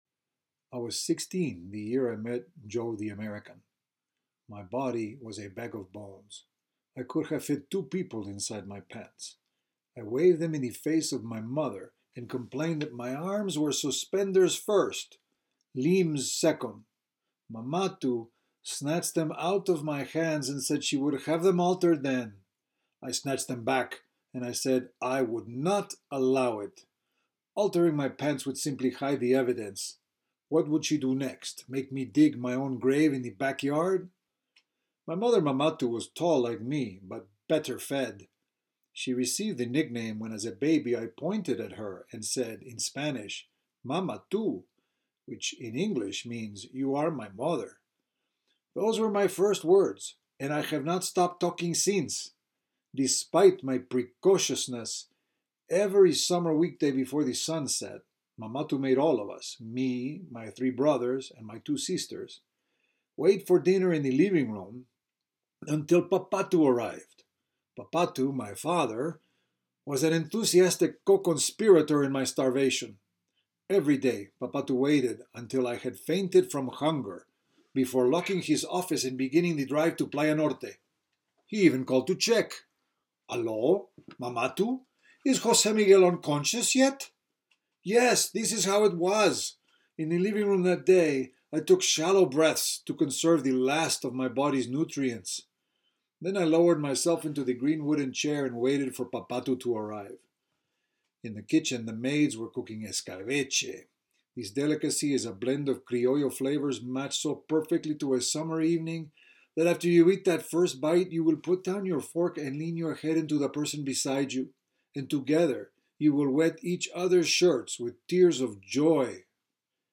Light Peruvian accent